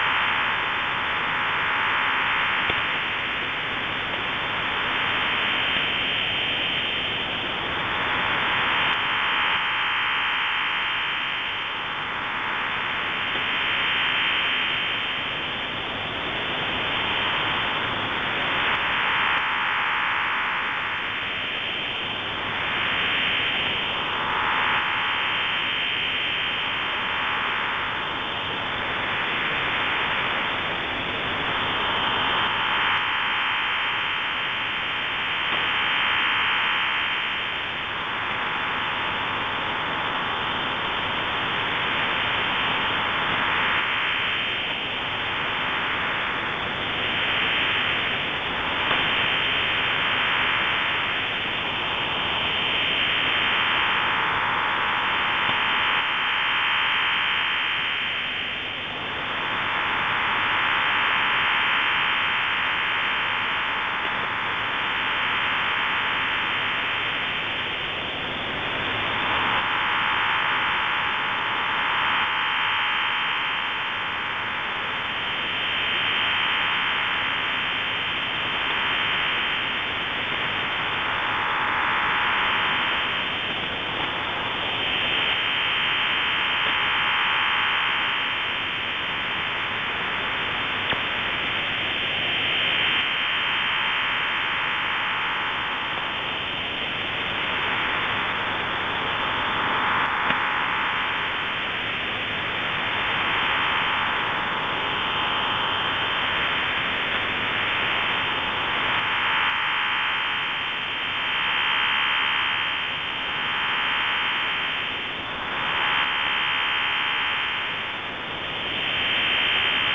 Специальные радиосистемы
Запись OFDM